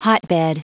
Prononciation du mot hotbed en anglais (fichier audio)
Prononciation du mot : hotbed